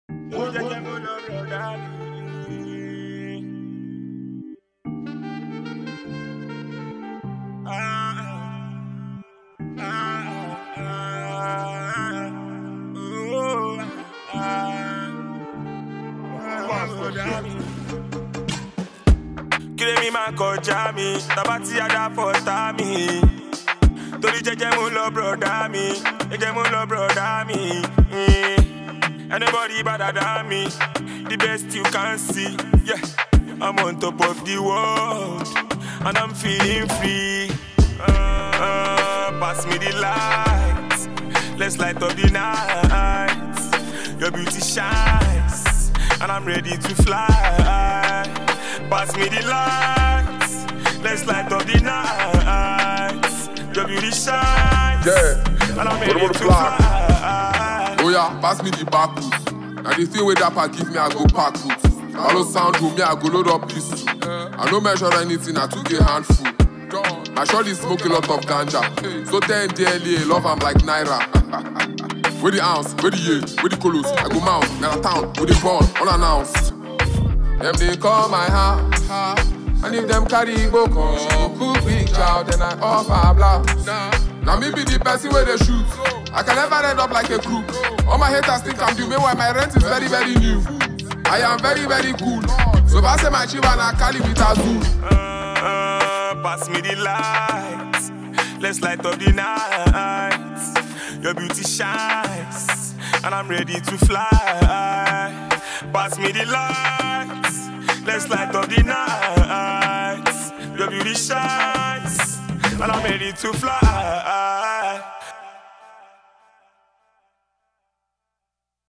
Buzzing talented Nigerian singer